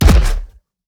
footsteps / generic